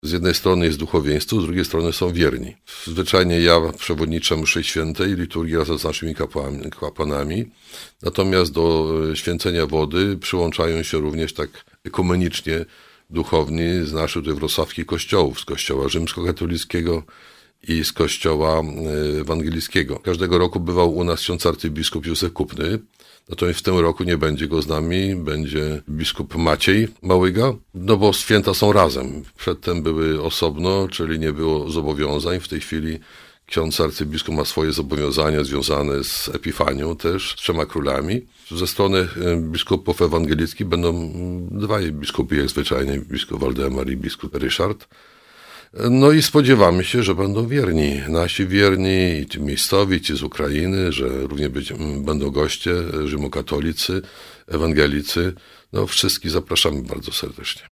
-Wydarzenie będzie miało ekumeniczny charakter – dodaje ks. Biskup.